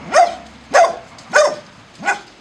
dog-dataset
puppy_0012.wav